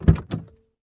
opendoor.ogg